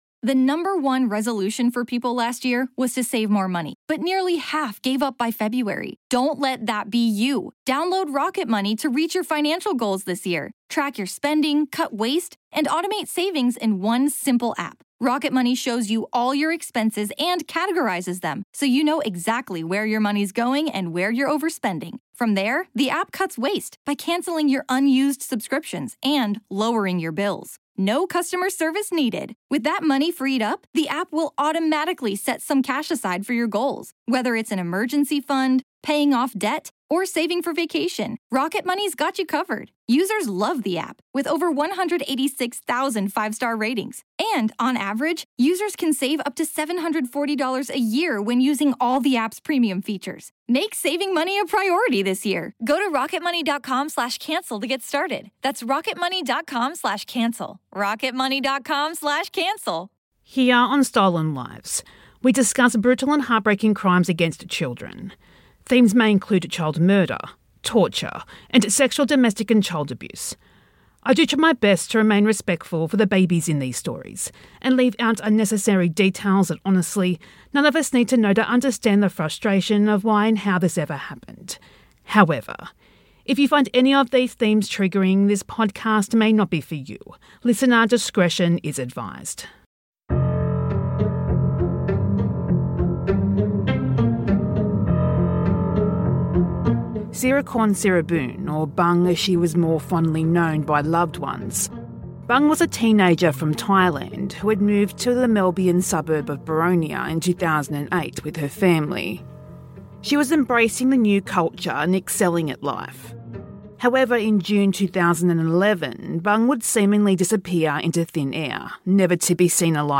This is a re-release with sped up audio and gaps of silence removed.